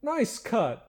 Add voiced sfx
nicecut0.ogg